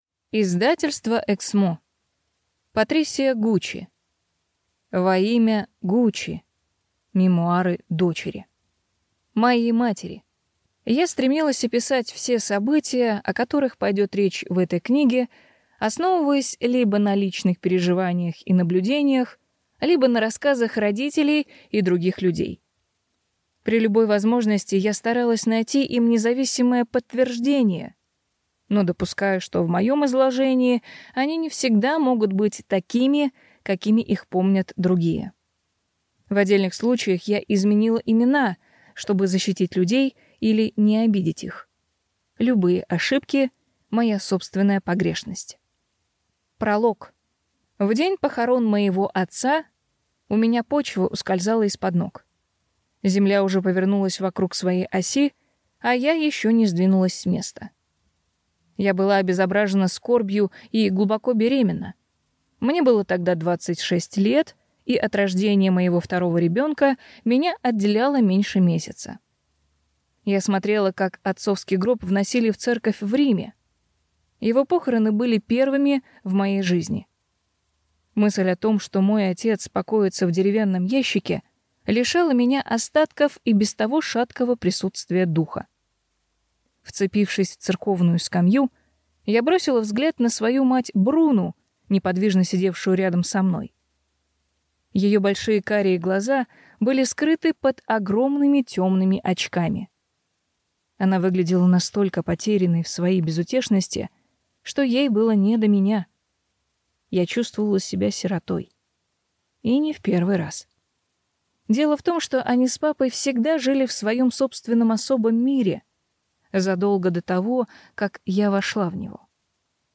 Аудиокнига Во имя Гуччи. Мемуары дочери | Библиотека аудиокниг